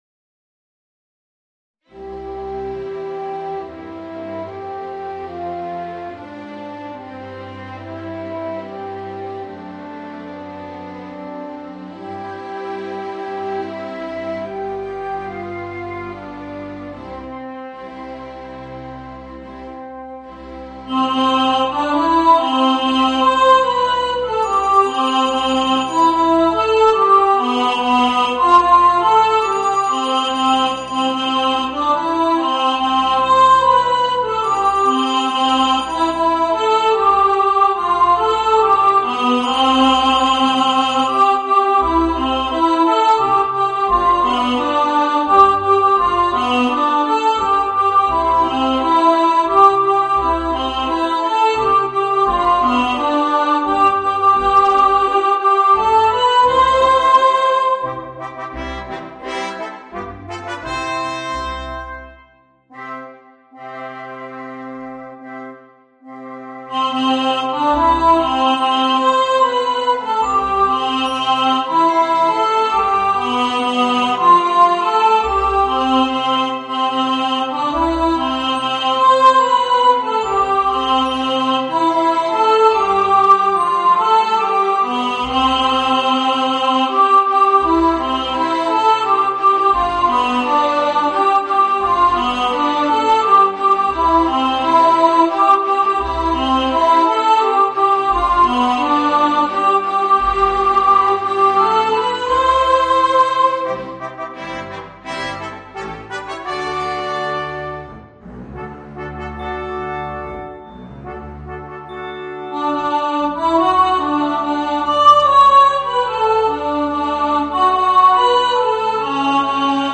Voicing: Children's Choir and Orchestra